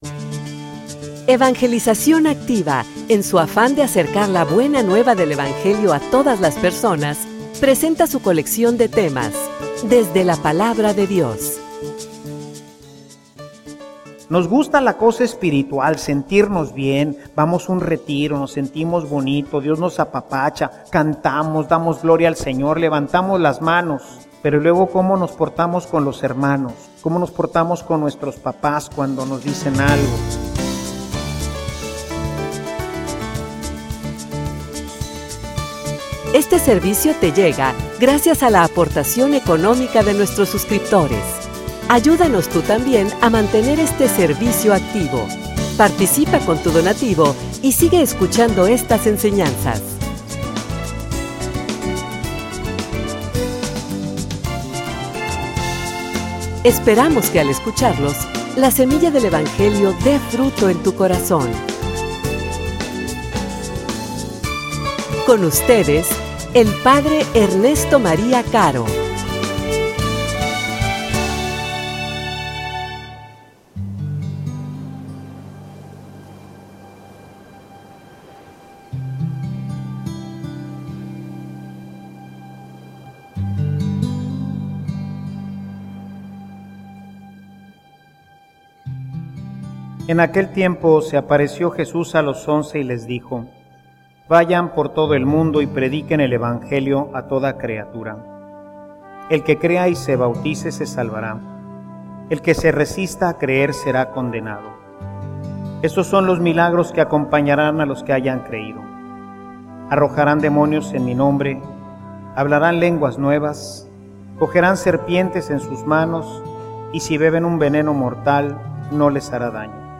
homilia_Cooperador_o_comprometido.mp3